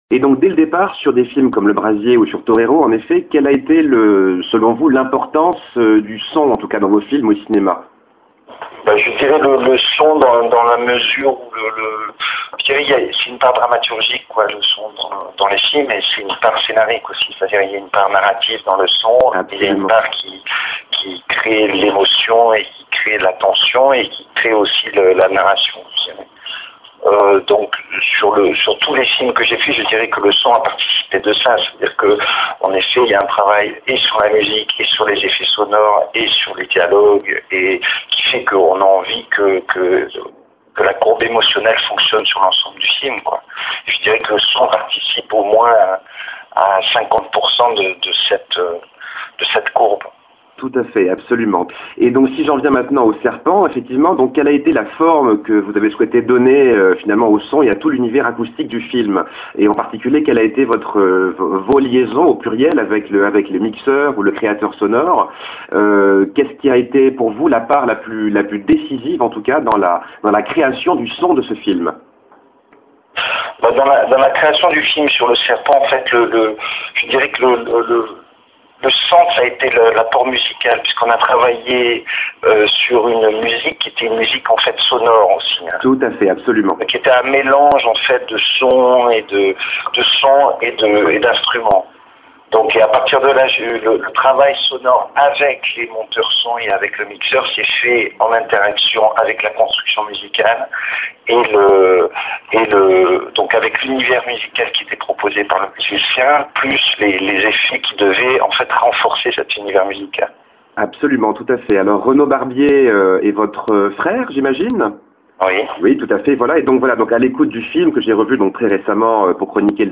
Interview de Eric Barbier travail sur le son du Serpent
Entretien téléphonique